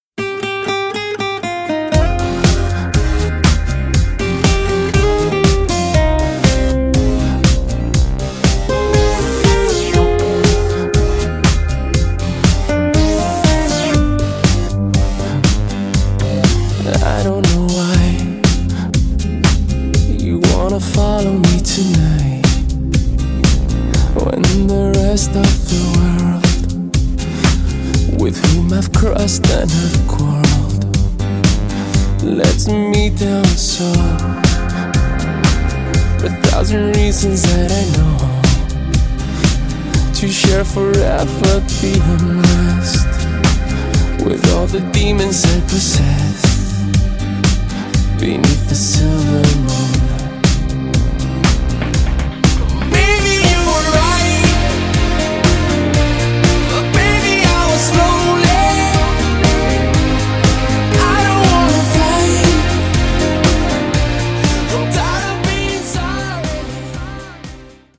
Романтические рингтоны